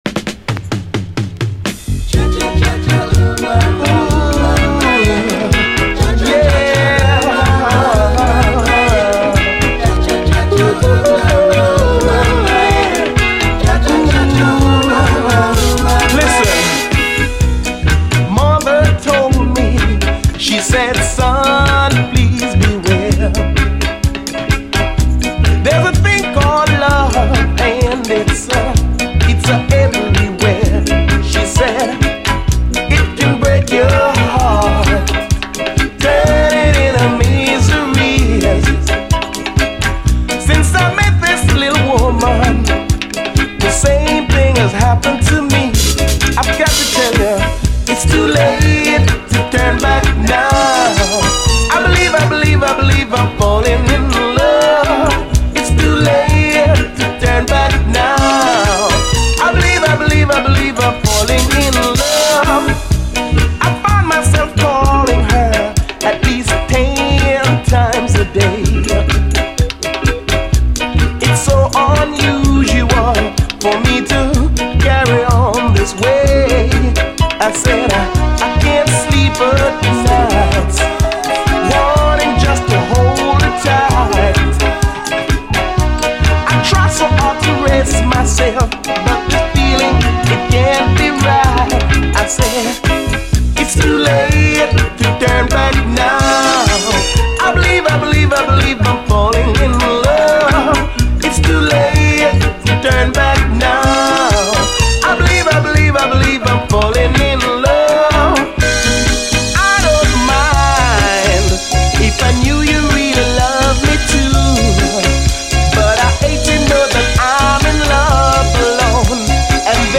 REGGAE
イントロの♫チャチャチャ・コーラスやギラッと光るシンセ使いなどアレンジ・ワークが素晴らしいグレイト・チューン！